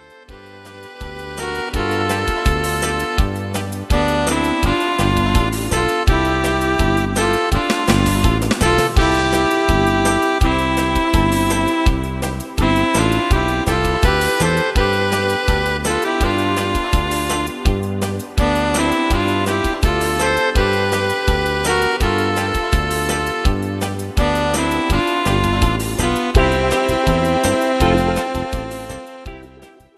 instrumental Orchester